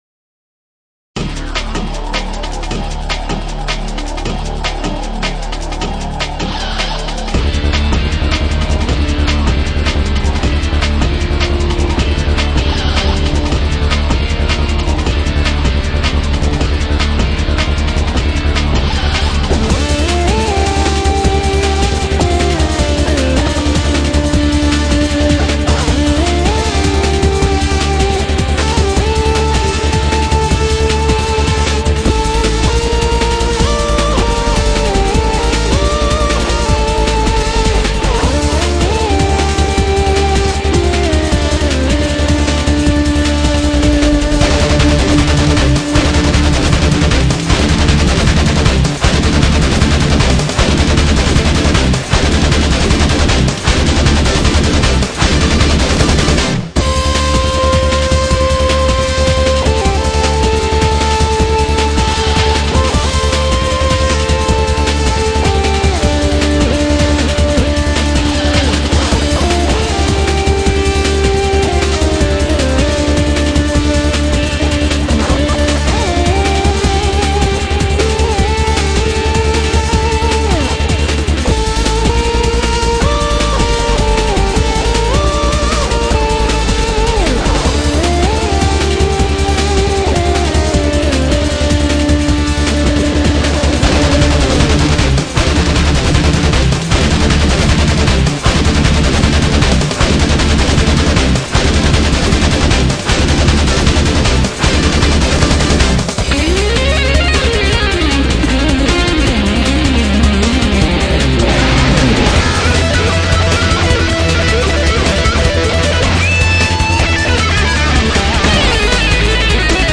0239-吉他名曲使魔鬼滑动.mp3